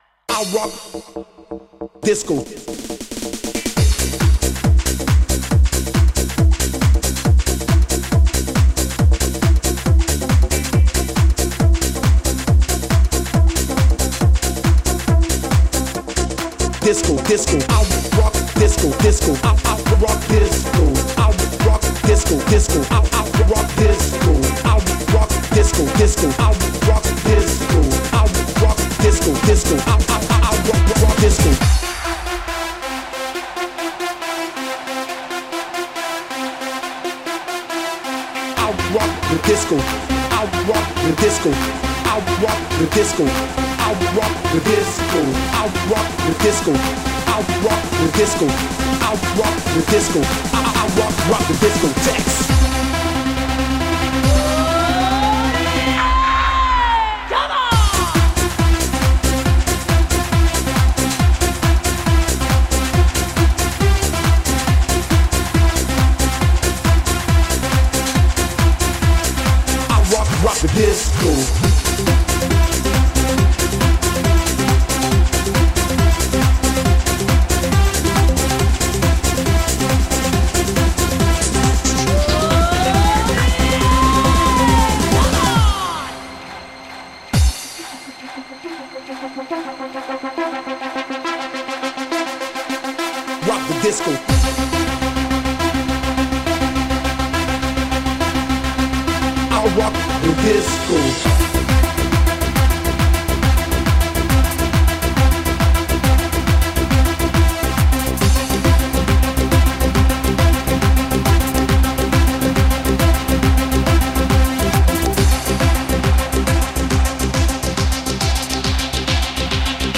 Genre: Club.